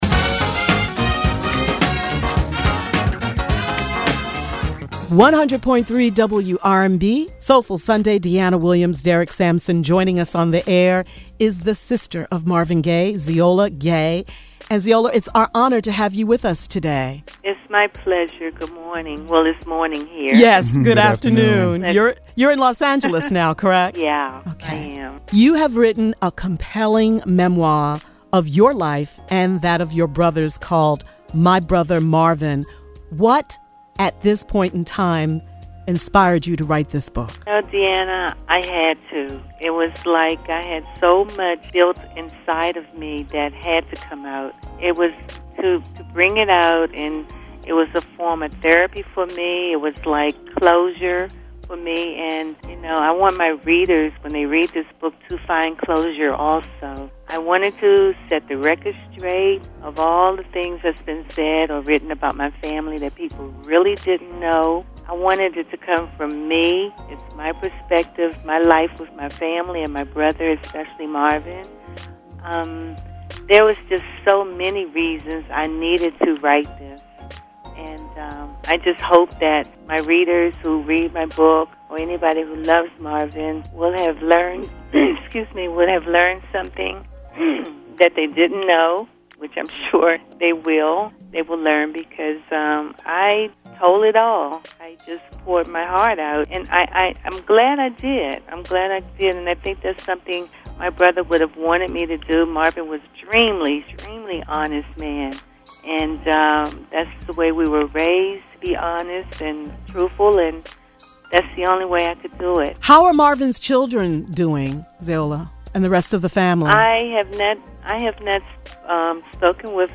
Edited Interview